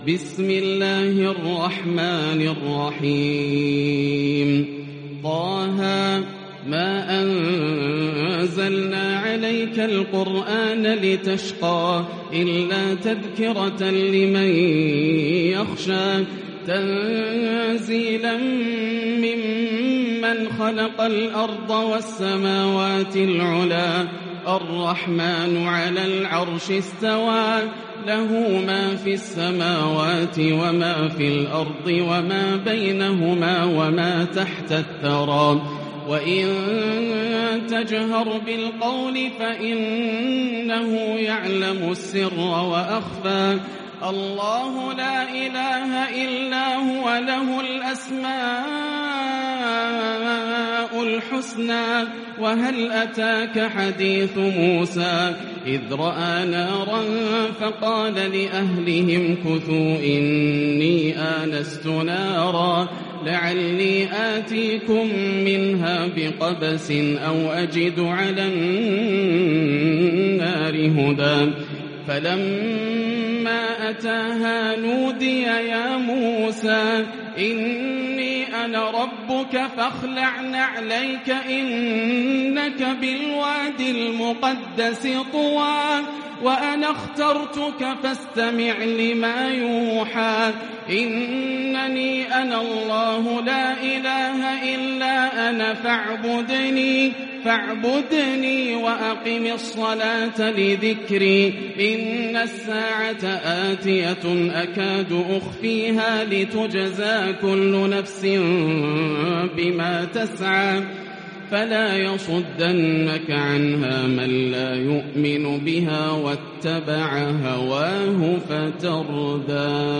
فواتح سورة طه بأعذب ترتيل روائع ليلة 21 رمضان 1442هـ > الروائع > رمضان 1442هـ > التراويح - تلاوات ياسر الدوسري